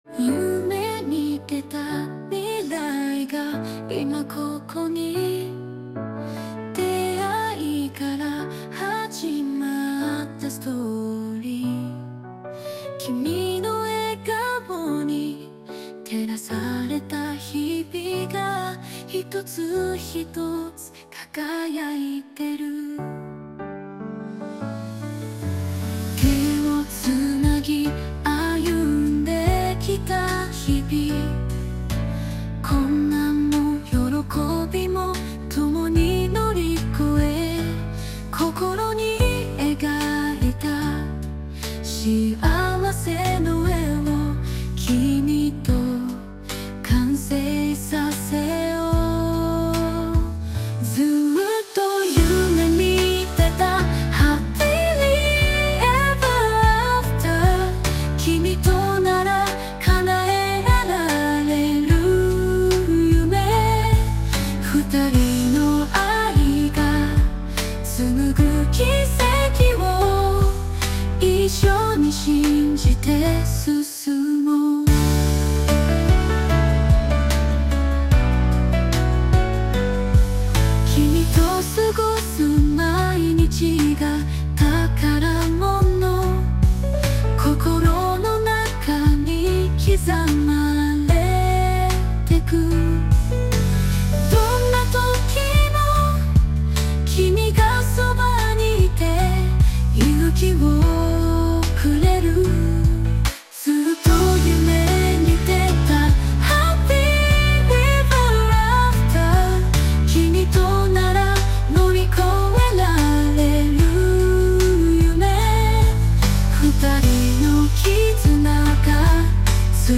女性ボーカル邦楽 女性ボーカルエンドロール
日本語・女性ボーカル曲です。